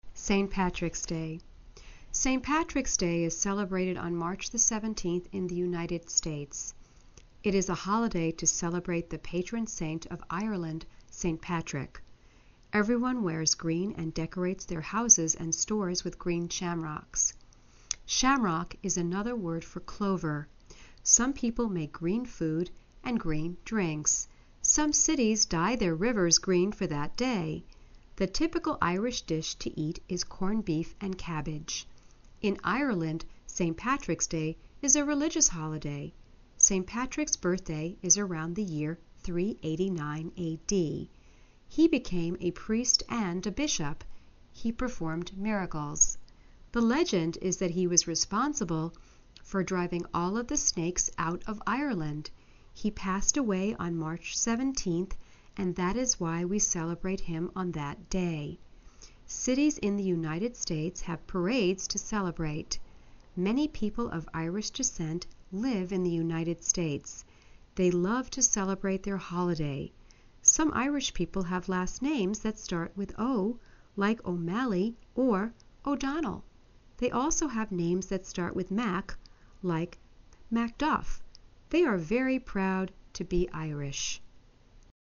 readings_level2_13b.mp3